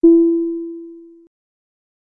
airplane-ding_25329.mp3